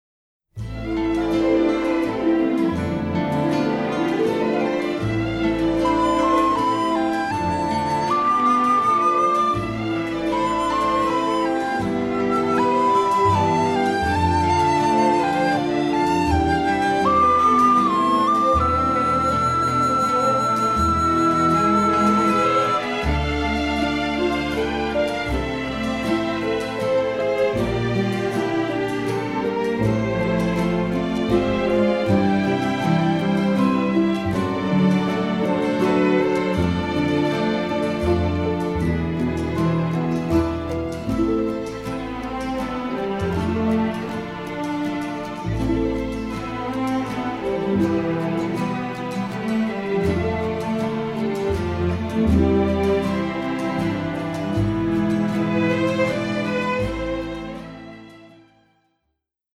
2CD soundtrack